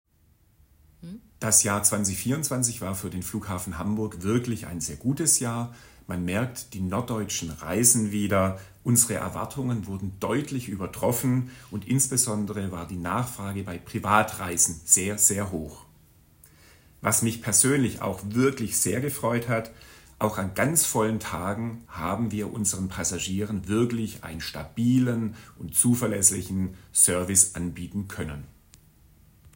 Audio-Statement